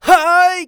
xys发力3.wav 0:00.00 0:00.68 xys发力3.wav WAV · 59 KB · 單聲道 (1ch) 下载文件 本站所有音效均采用 CC0 授权 ，可免费用于商业与个人项目，无需署名。
人声采集素材